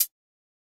Perc (2).wav